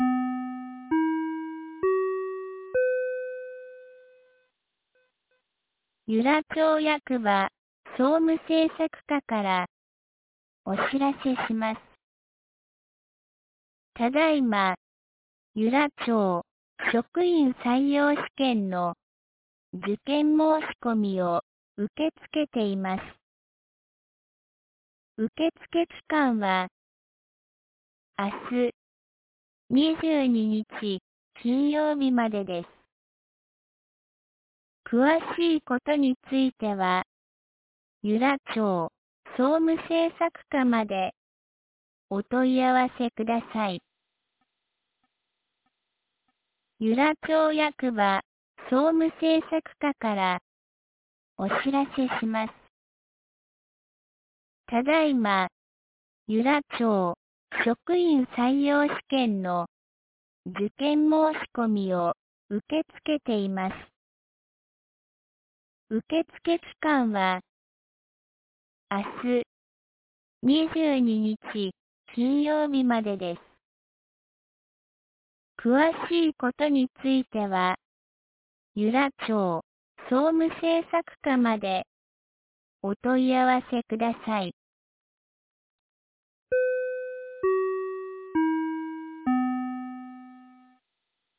2025年08月21日 17時12分に、由良町から全地区へ放送がありました。